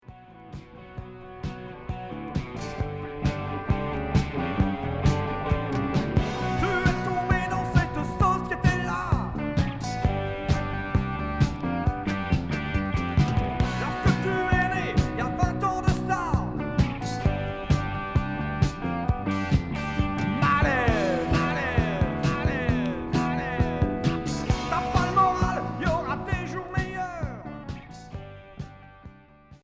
Hard rock Deuxième 45t retour à l'accueil